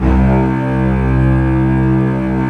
Index of /90_sSampleCDs/Roland - String Master Series/STR_Cbs Arco/STR_Cbs3 Bright